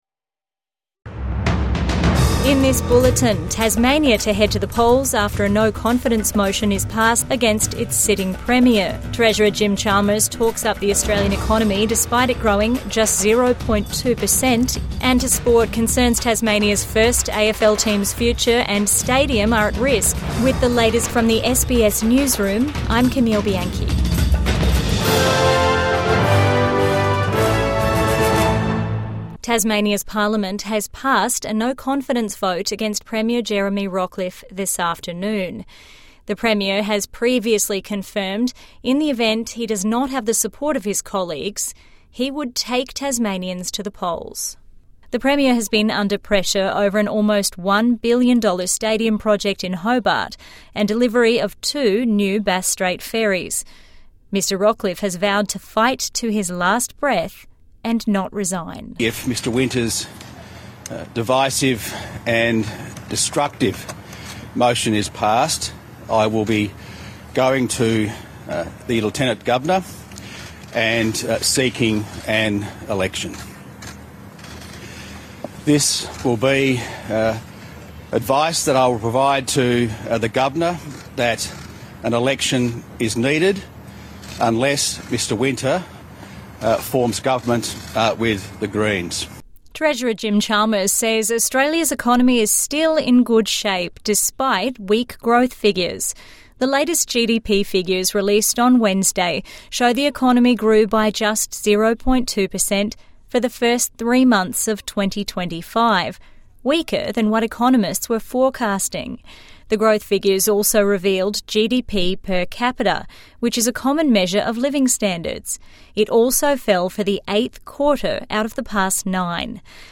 Tasmanian Premier moves to call a state election | Evening News Bulletin 5 June 2025